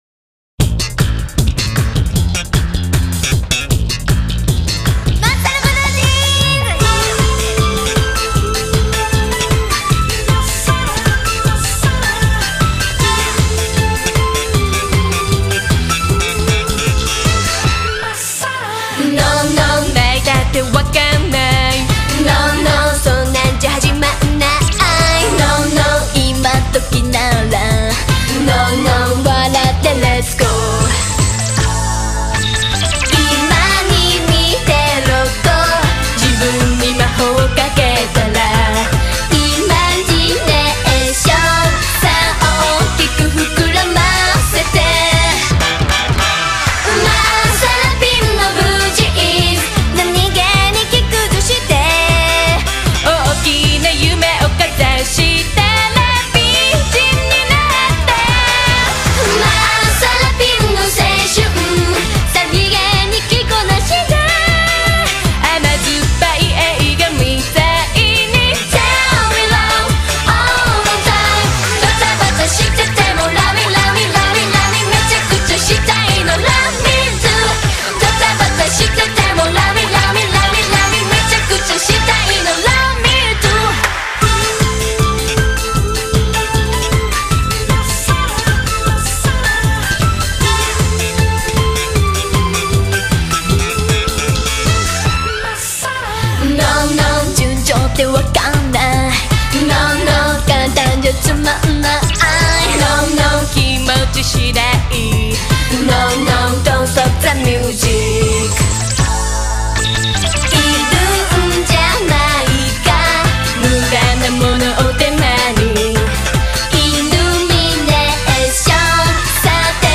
BPM77-155
Audio QualityCut From Video